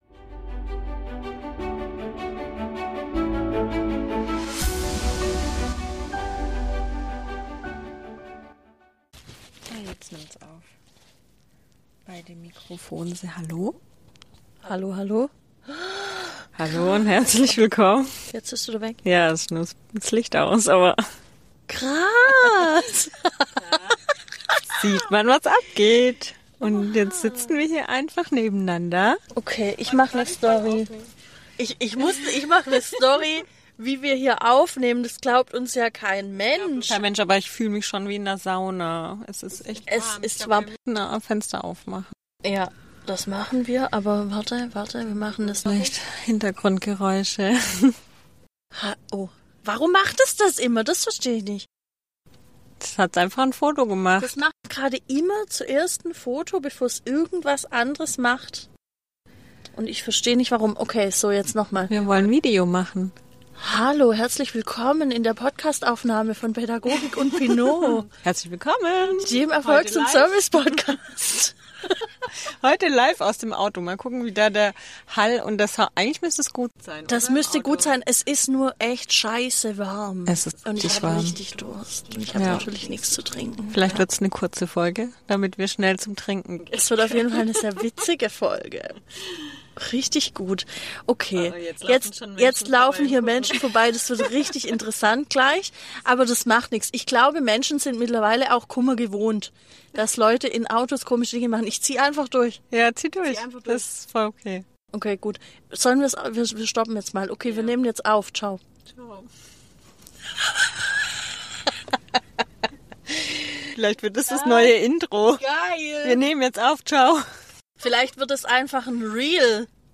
#66 Live aus dem Auto